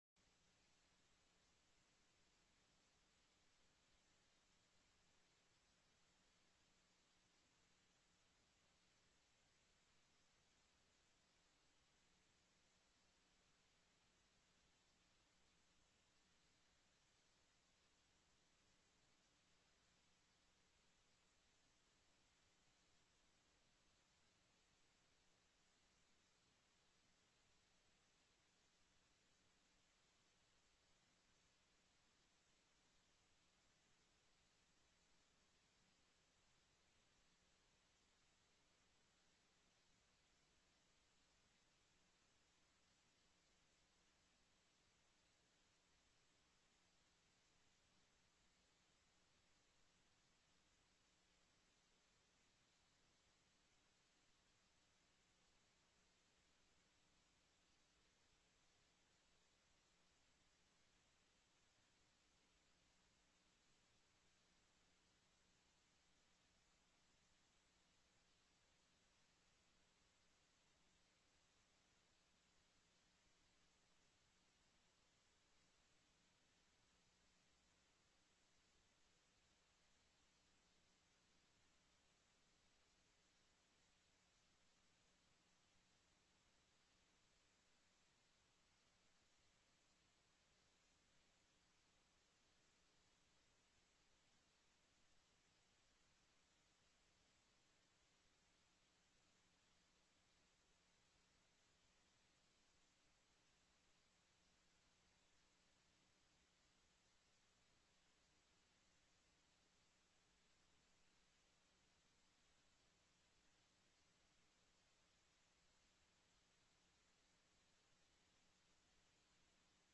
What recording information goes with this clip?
The audio recordings are captured by our records offices as the official record of the meeting and will have more accurate timestamps. Consideration of Governor's Appointees: TELECONFERENCED